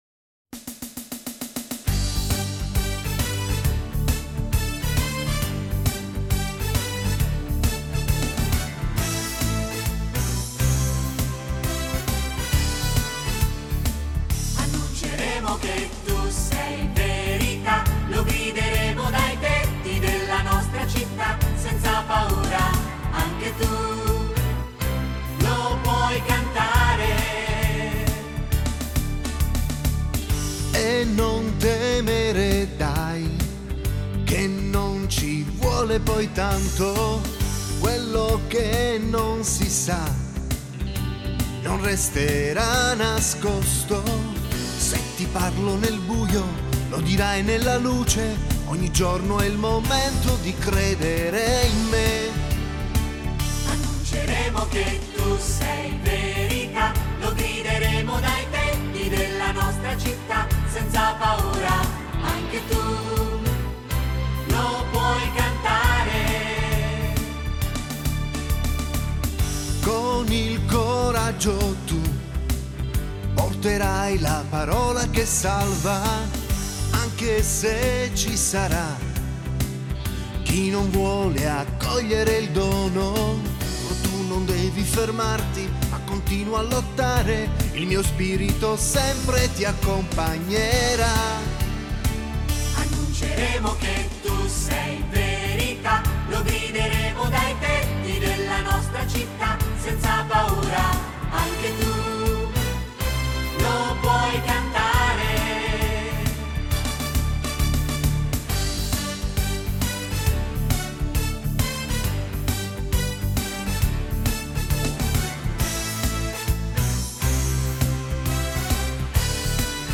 Canto